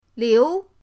liu2.mp3